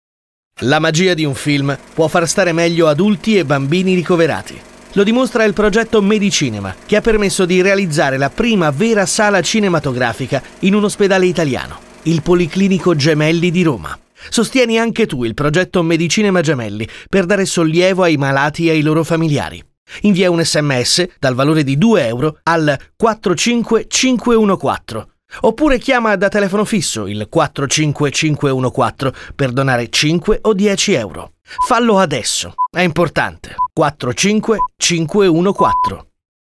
spot radiofonico